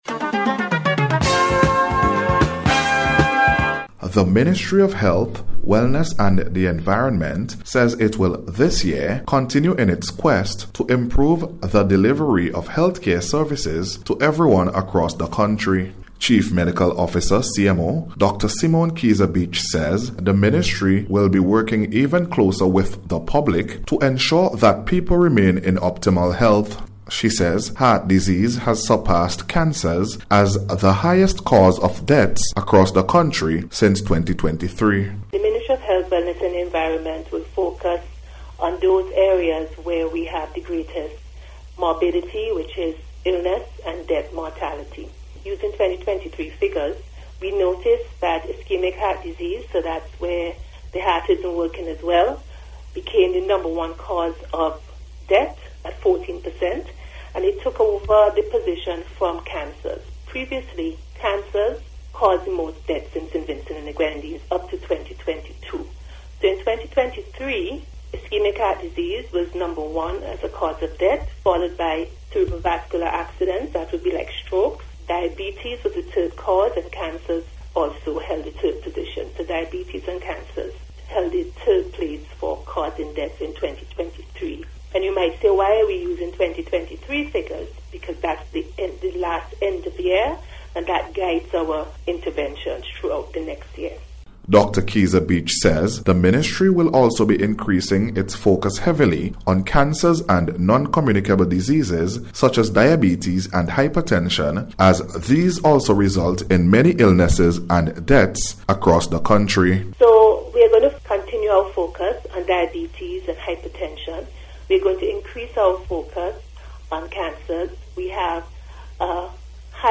That’s according to Chief Medical Officer, Dr. Simone Keizer-Beache.